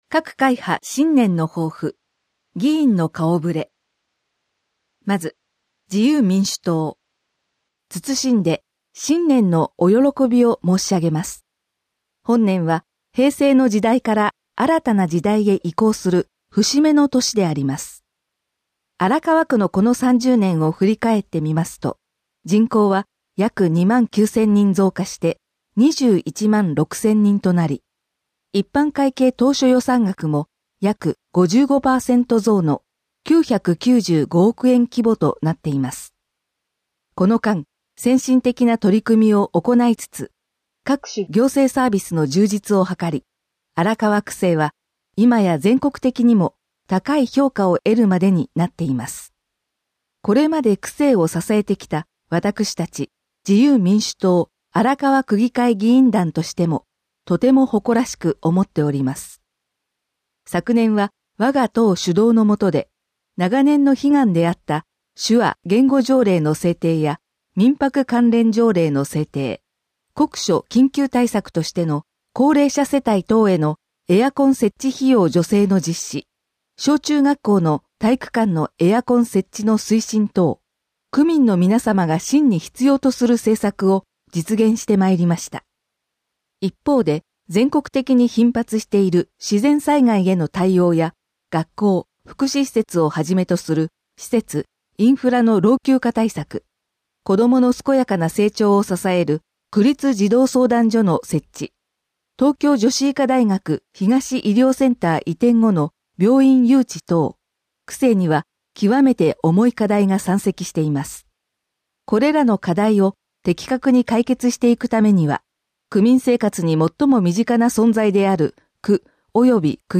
声の区議会だより 平成30年度新年号（平成31年1月1日発行）